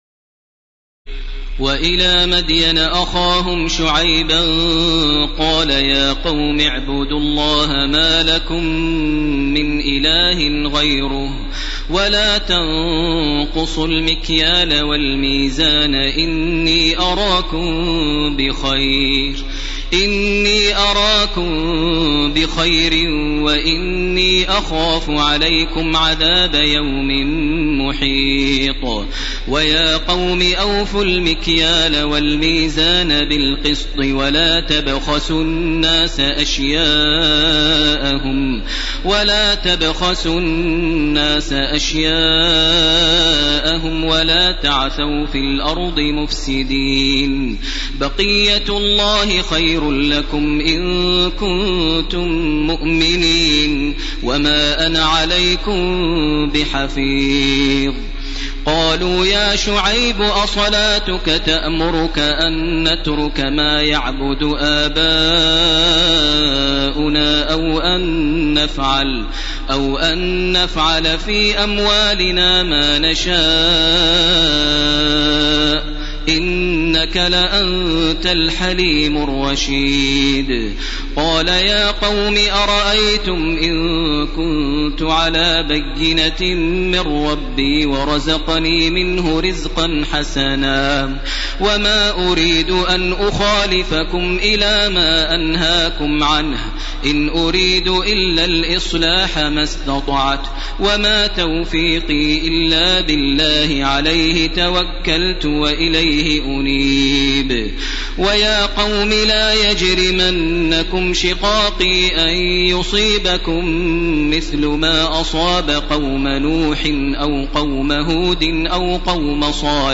تراويح الليلة الثانية عشر رمضان 1431هـ من سورتي هود (84-123) و يوسف (1-57) Taraweeh 12 st night Ramadan 1431H from Surah Hud and Yusuf > تراويح الحرم المكي عام 1431 🕋 > التراويح - تلاوات الحرمين